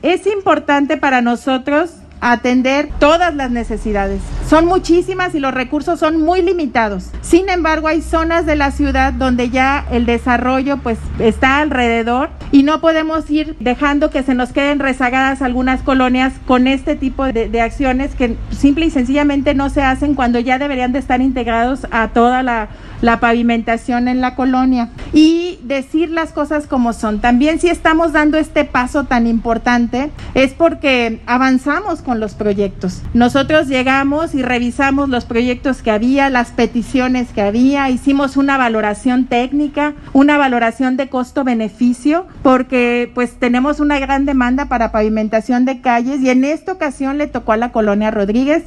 AudioBoletines
Lorena Alfaro García – Presidenta Municipal